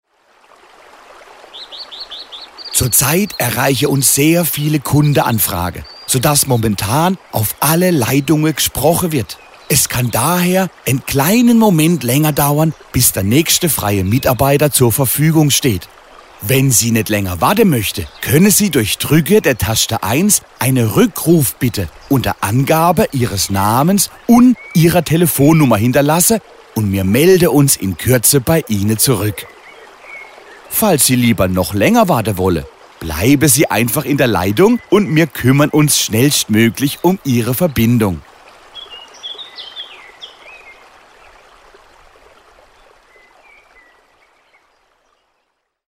Badische Telefonansage – Telefonansage badisch
Überlauf Ansage